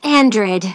synthetic-wakewords
ovos-tts-plugin-deepponies_Starlight_en.wav